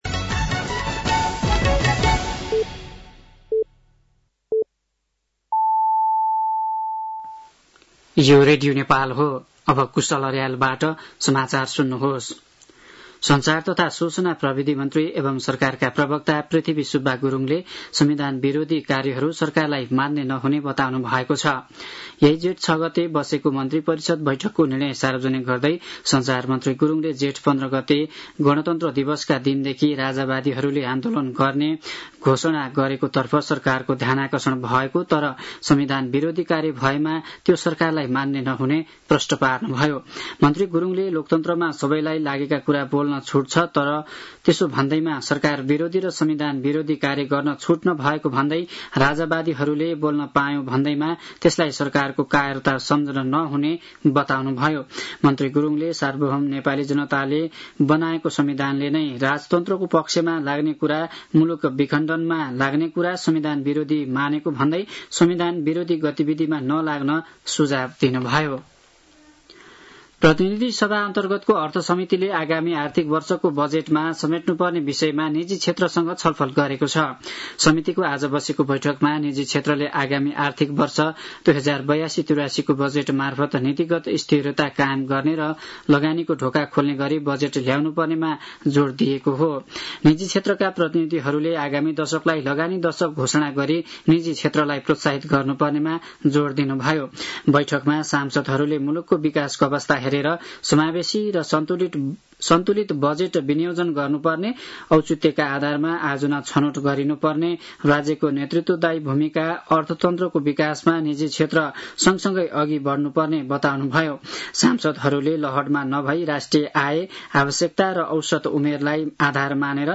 साँझ ५ बजेको नेपाली समाचार : ८ जेठ , २०८२
5.-pm-nepali-news-1-2.mp3